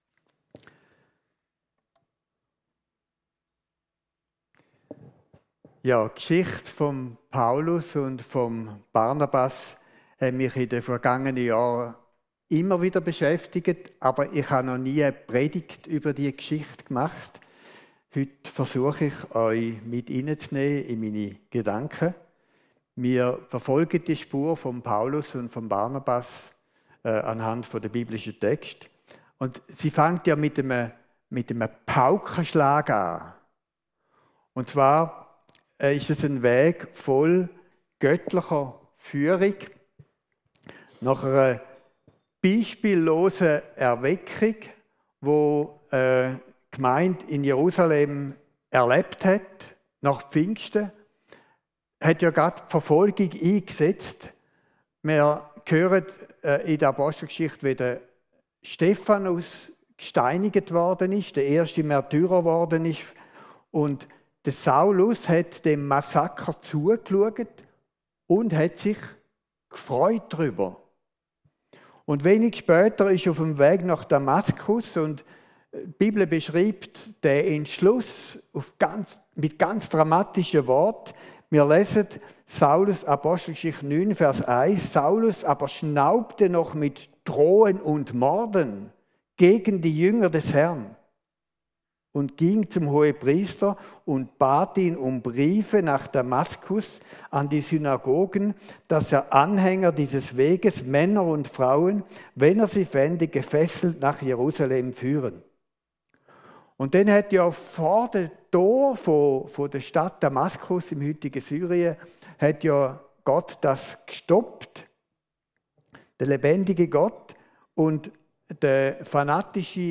Predigt
Hier hörst du die Predigten aus unserer Gemeinde.